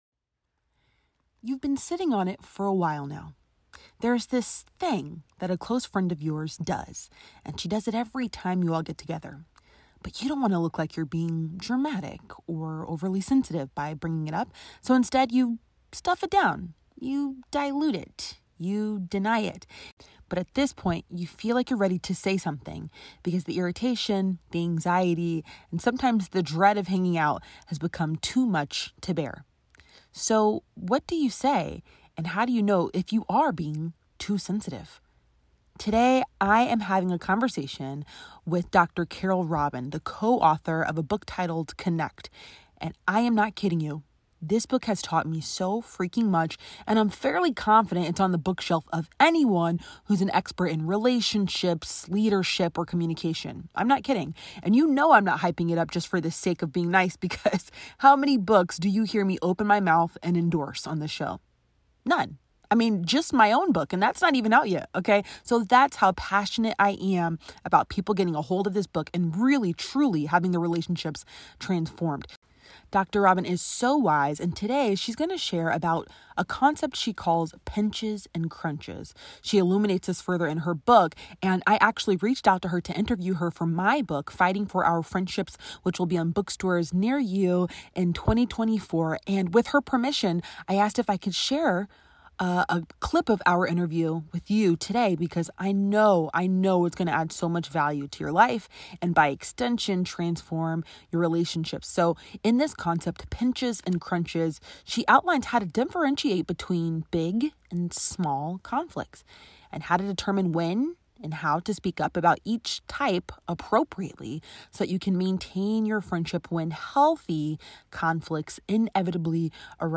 She said YES, and the interview was too good not to share with you.